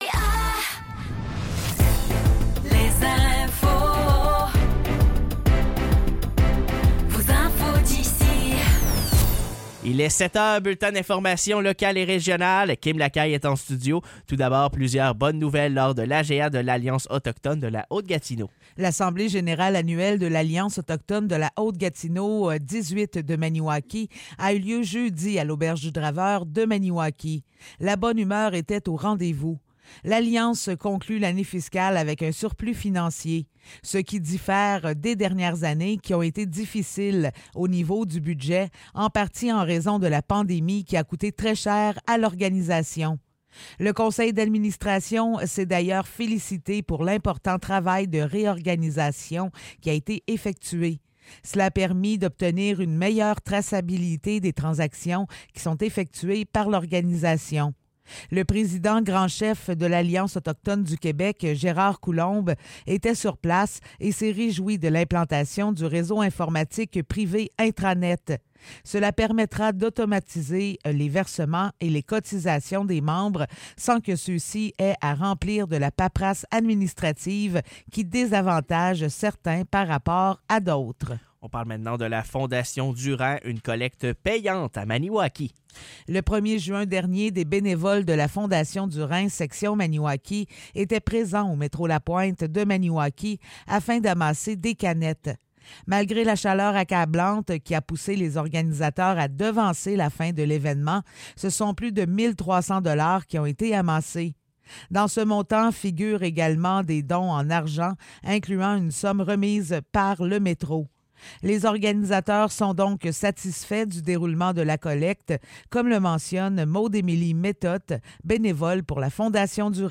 Nouvelles locales - 11 juin 2024 - 7 h